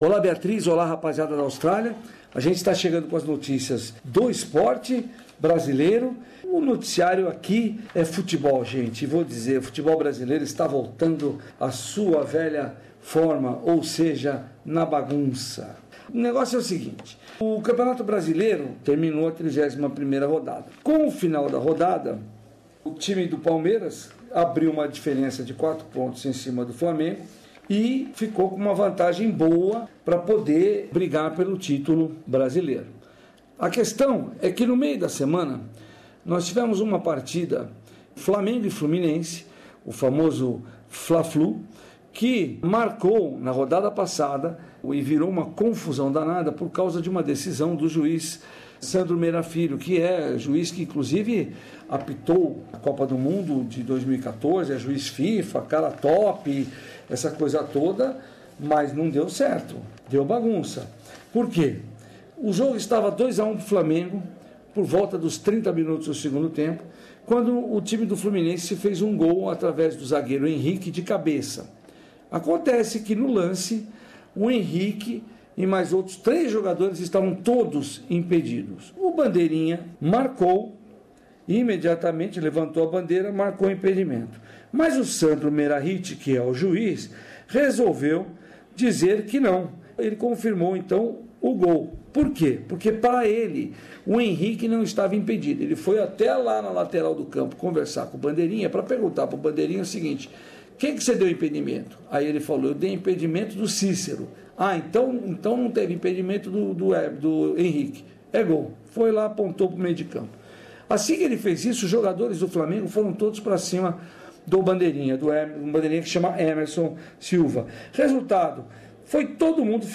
Boletim semanal do correspodente esportivo do Programa Português da Rádio SBS no Brasil